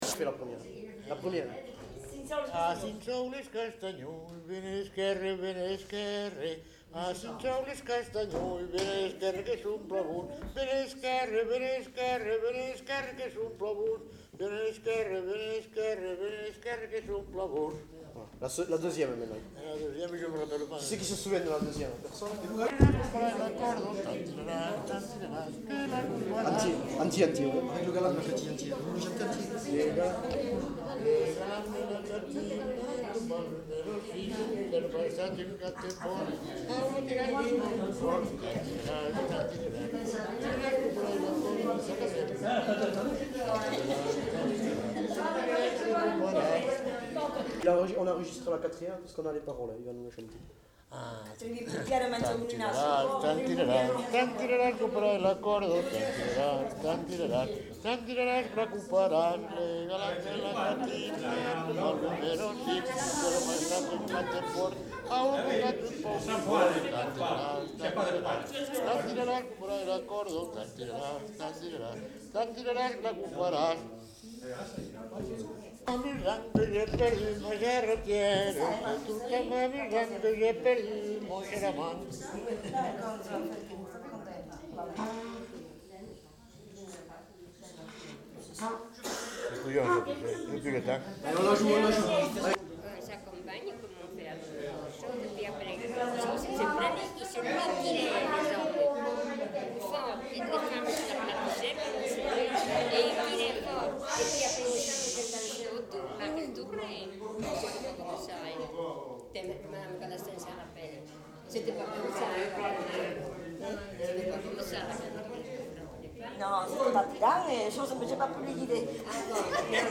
Aire culturelle : Lauragais
Lieu : Lanta
Genre : chant
Effectif : 1
Type de voix : voix d'homme
Production du son : chanté
Danse : quadrille